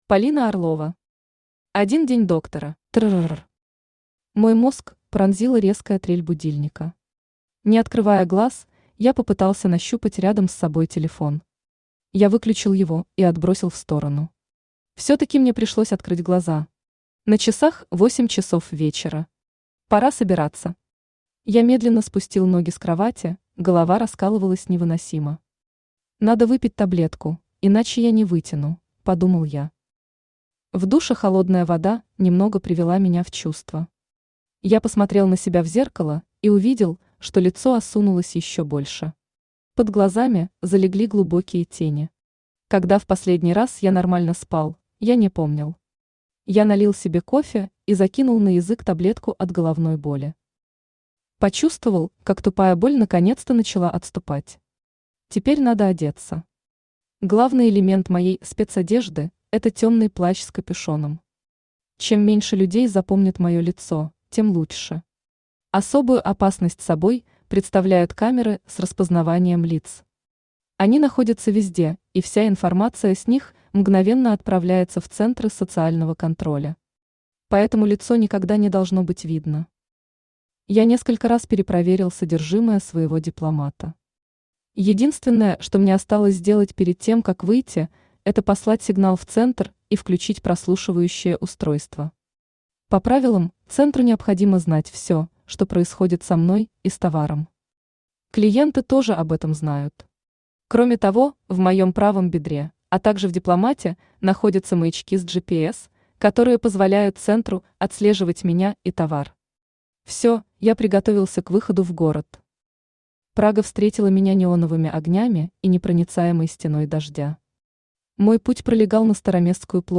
Aудиокнига Один день доктора Автор Полина Орлова Читает аудиокнигу Авточтец ЛитРес.